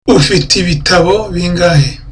(Friendly)